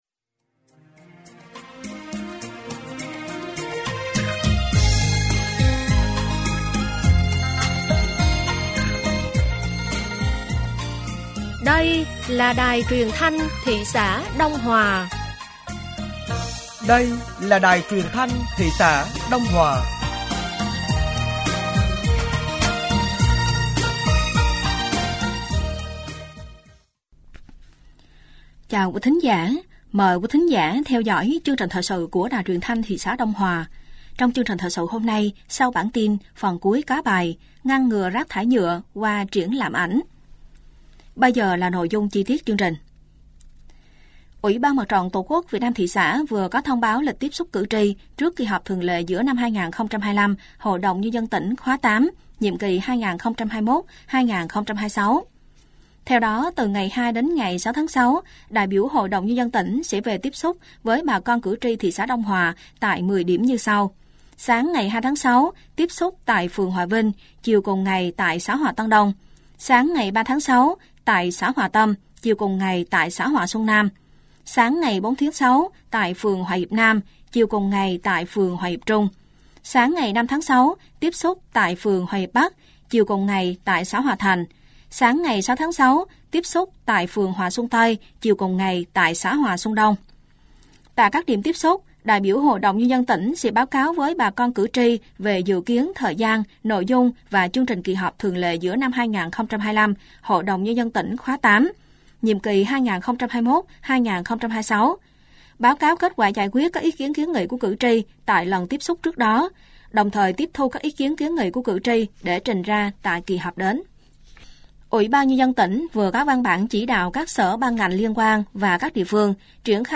Thời sự tối ngày 31/5/2025 sáng ngày 1/6/2025